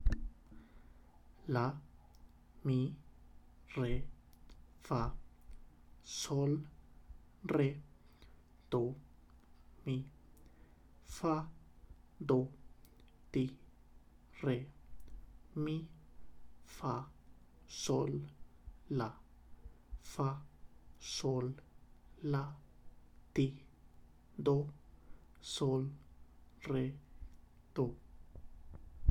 Exercise 2 – Spoken